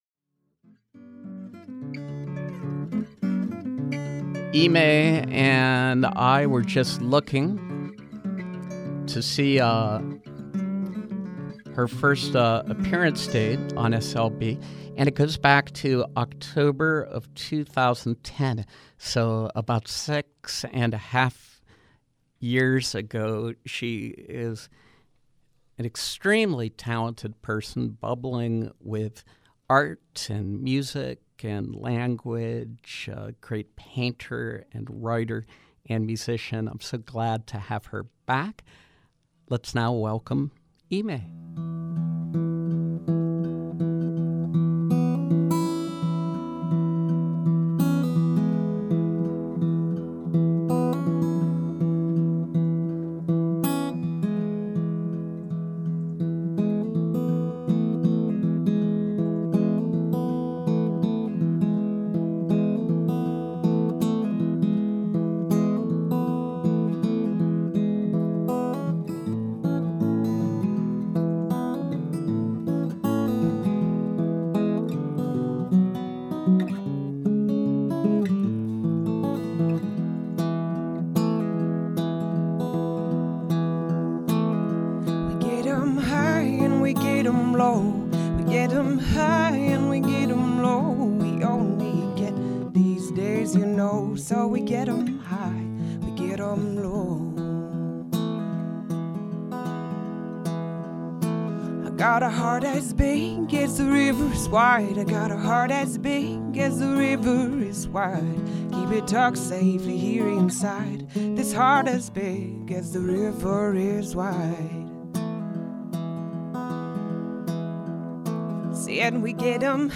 Live acoustic performance